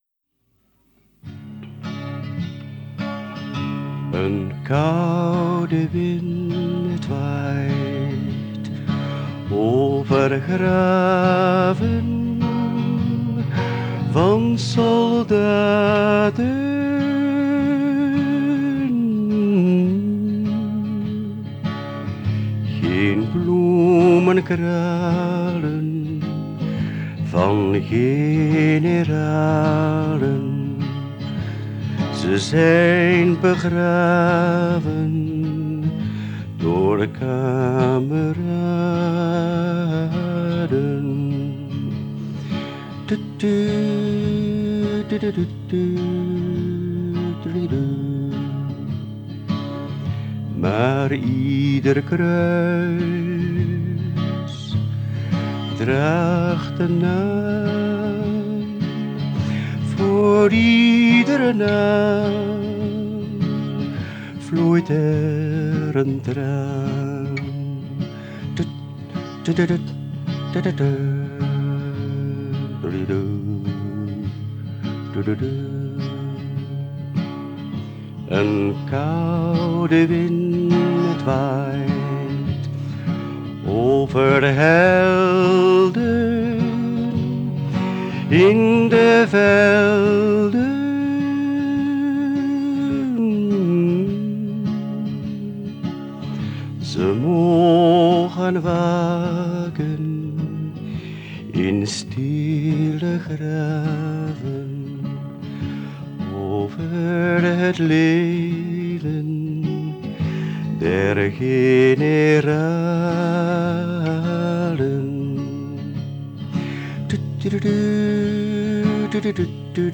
so, dass es jeden Zuhörer friert.